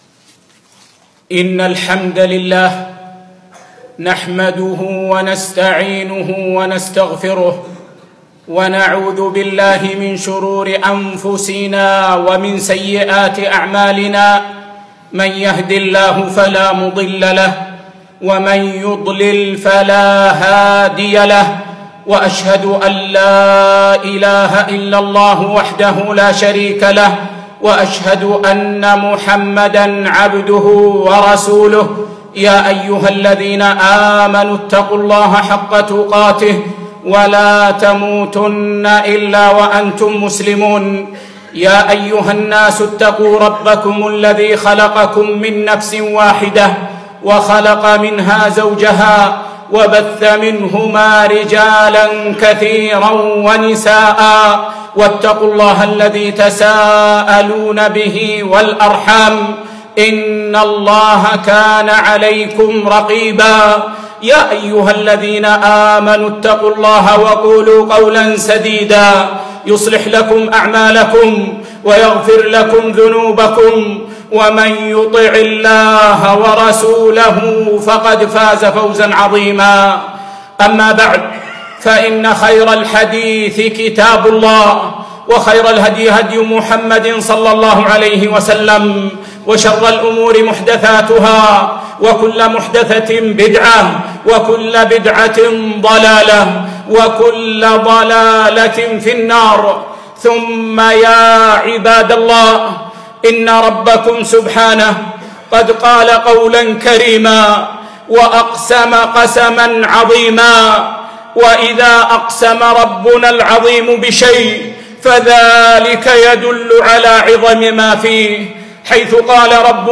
يوم الجمعة 9 3 2018 في مسجد العلاء بن عقبة منطقة الفردوس
فلاح النفس وتزكيتها - خطبة